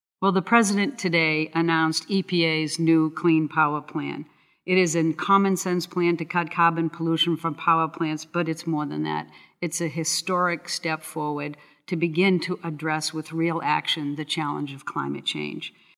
Speaker: Gina McCarthy, EPA Administrator
Radio Actualities